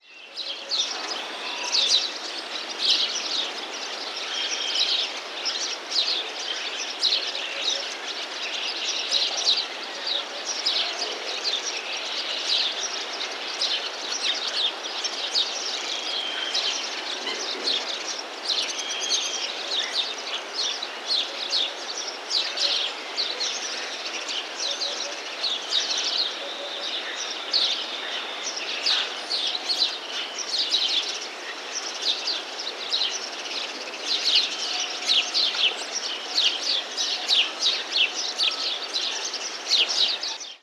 Ambiente de campo con pájaros en primavera
Sonidos: Animales
Sonidos: Rural
Aves